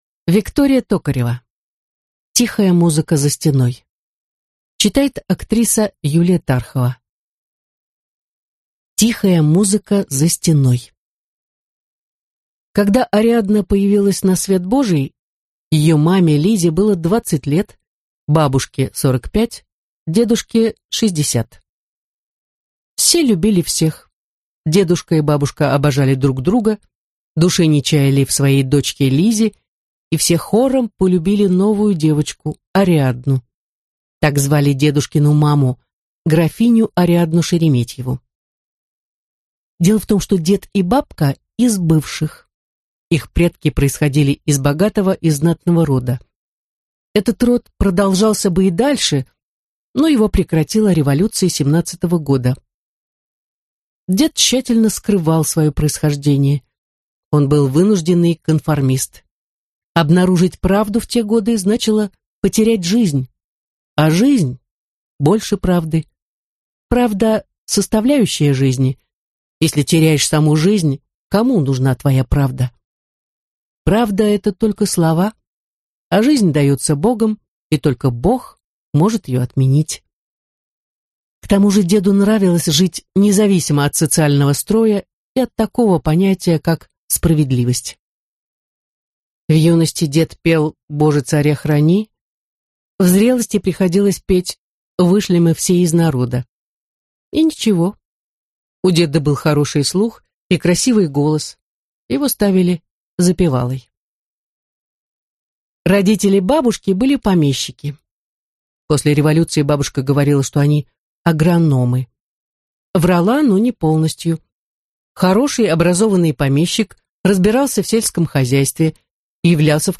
Аудиокнига Тихая музыка за стеной (сборник) | Библиотека аудиокниг